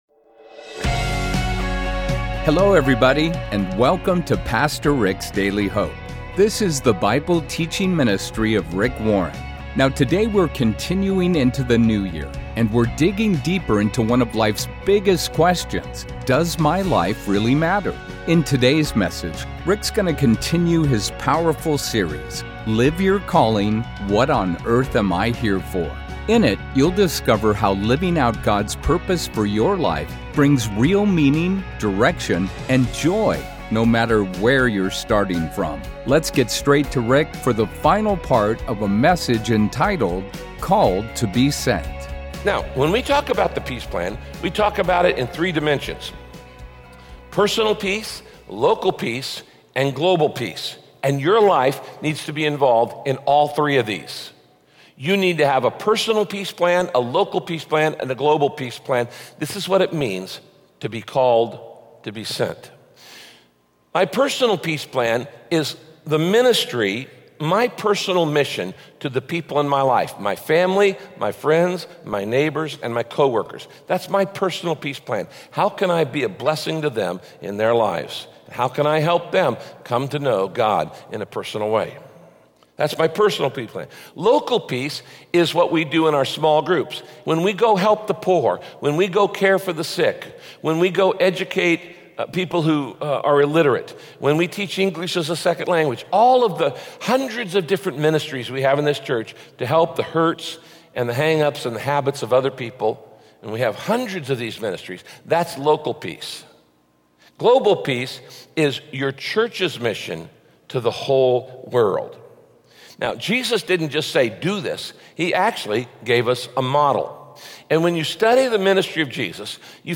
From the very beginning, God's plan has been to make you like his Son, Jesus. In this message, Pastor Rick explores some of the aspects you share with God and h…